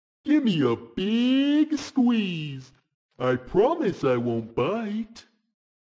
На этой странице собрана коллекция звуков и голосовых фраз Haggy Wagy.